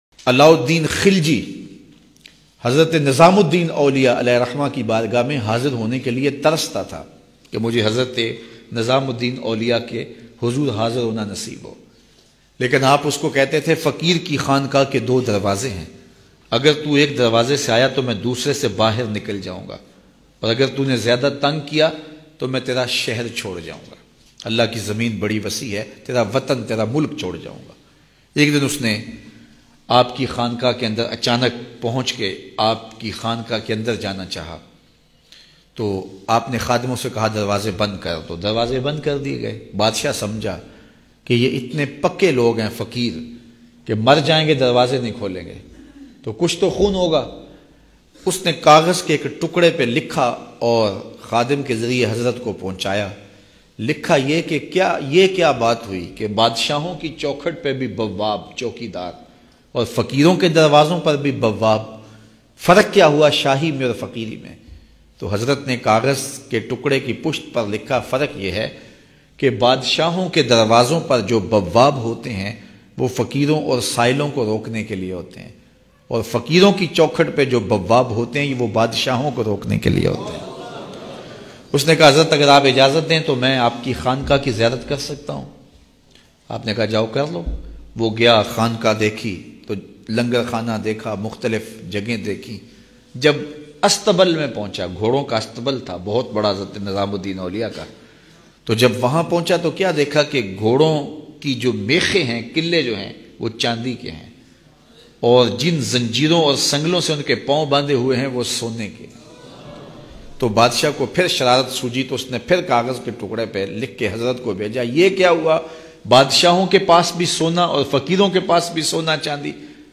Agr Muje Zyada Tang Kiya Gya Bayan MP3